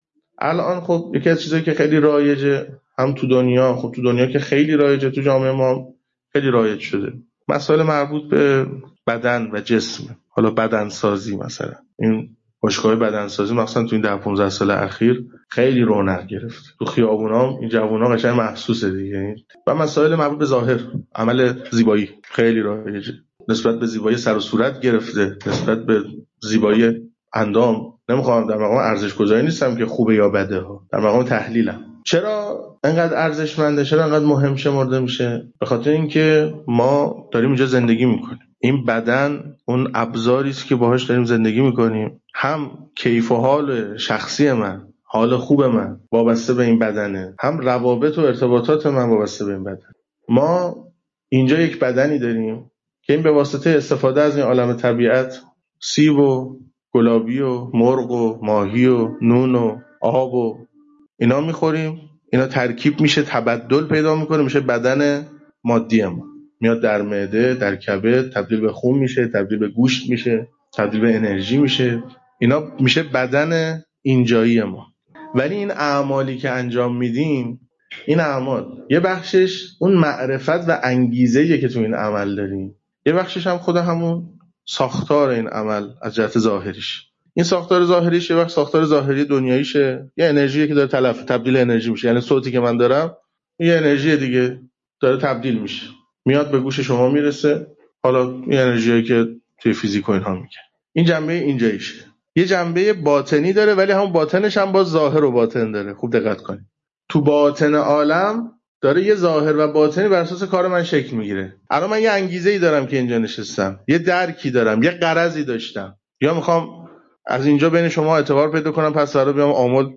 برگرفته از جلسه باشگاه بدن سازی